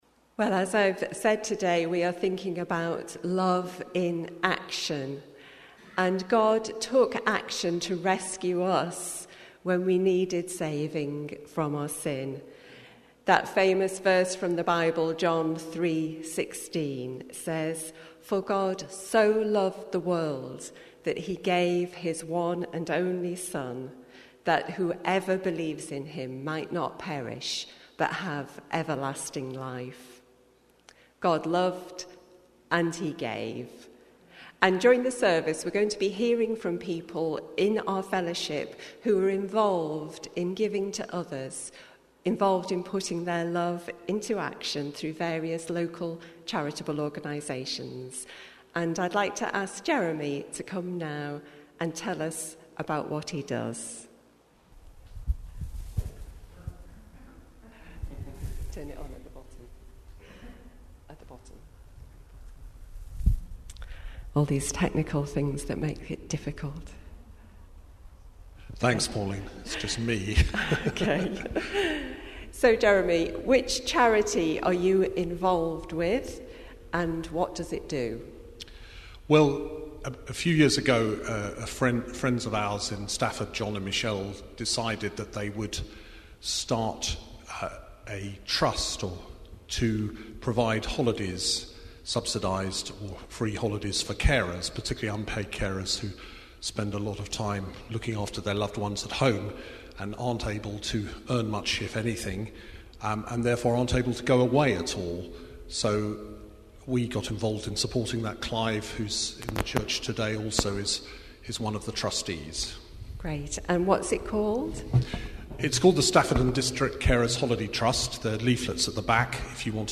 Today's sermon contains a series of interviews with members of our church family as we look at how people have served others through COVID and beyond.